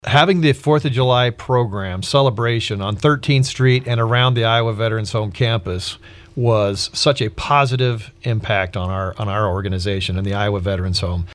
That is Iowa Commandant Todd Jacobus who has made his office at the Iowa Veterans Home.  He says they can transport residents but that does limit the number of people who can attend events.